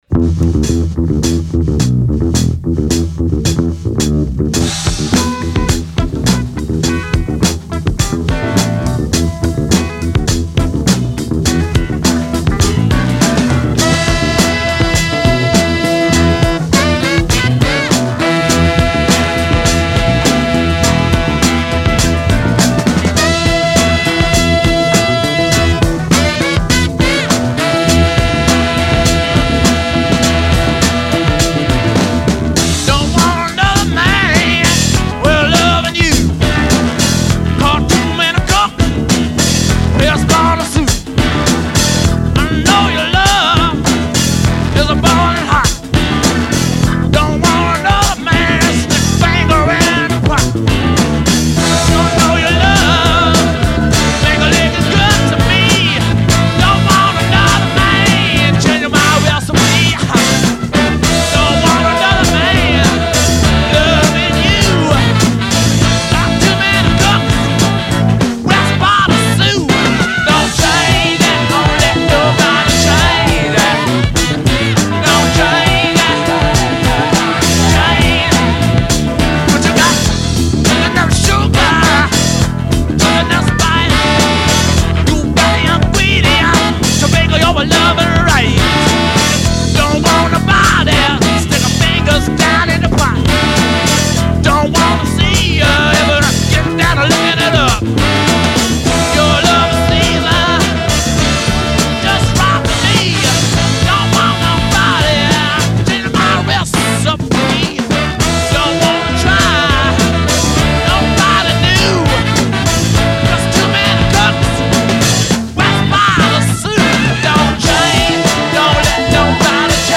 bass
backing vocals
a greasy concoction of bass and punchy horns